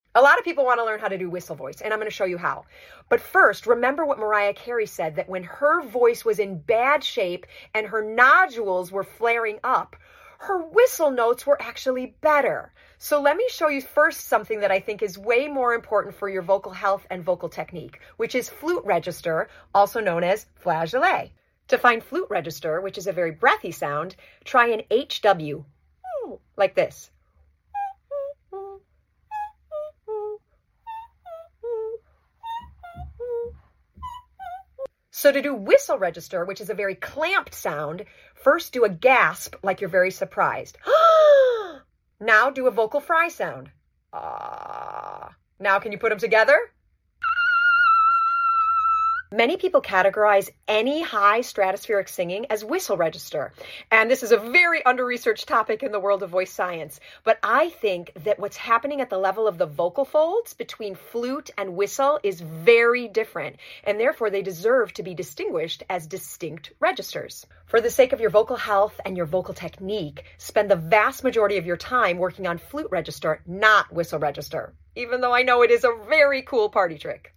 Whistle register vs flute register tutorial!
This video will help you distinguish flute vs. whistle in your own voice!